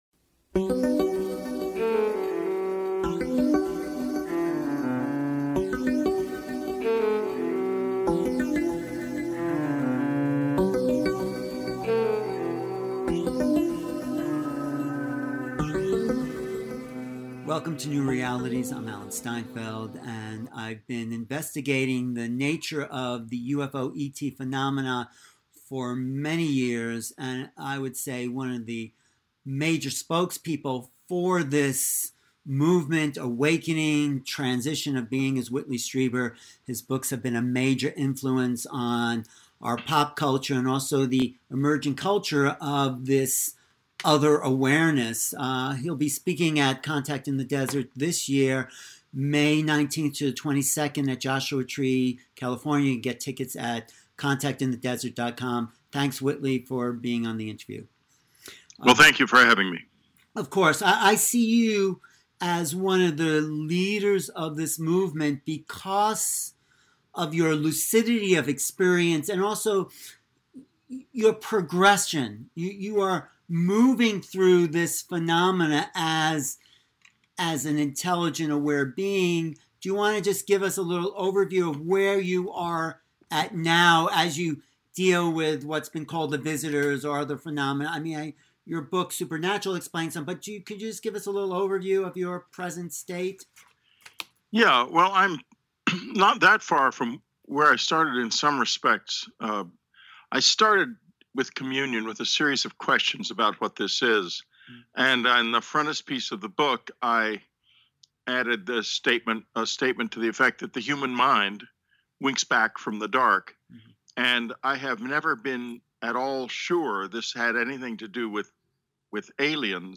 Talk Show Episode, Audio Podcast, New Realities and with Whitley Strieber on , show guests , about Whitley Strieber,ET Contact, categorized as Paranormal,UFOs,Near Death Experiences,Ghosts,Spiritual,Theory & Conspiracy